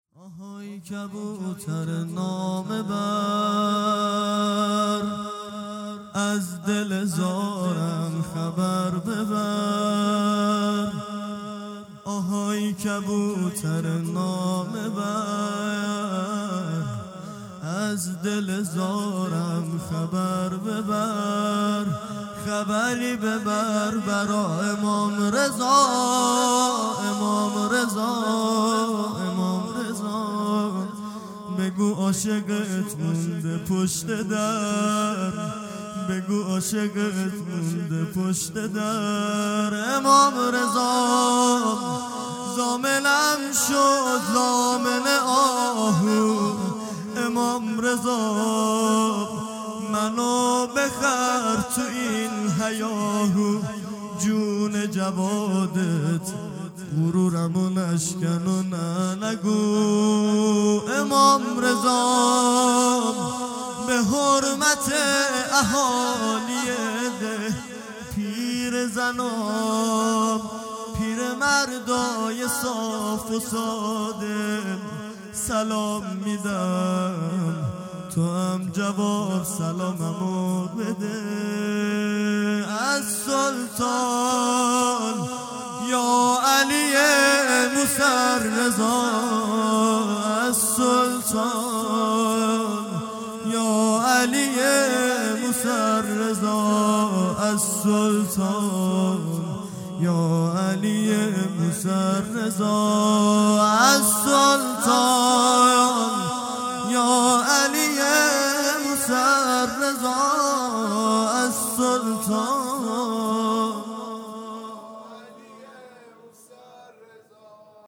مناجات
آهای کبوتر نامه بر|جلسه هفتگی ۴ دی ۹۷